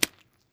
STEPS Pudle, Walk 07, Single Impact.wav